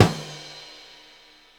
LOOP39SD08-L.wav